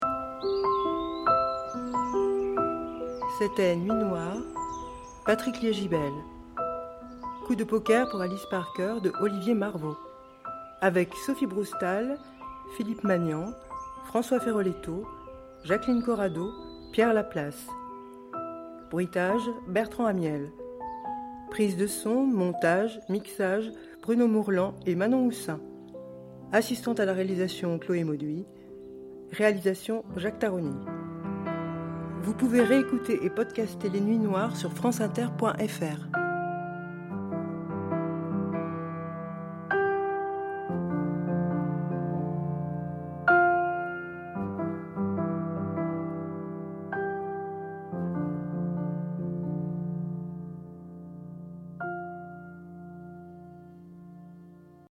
voix générique " Nuit noire